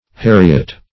Heriot - definition of Heriot - synonyms, pronunciation, spelling from Free Dictionary
Heriot \Her"i*ot\, n. [AS. heregeatu military equipment, heriot;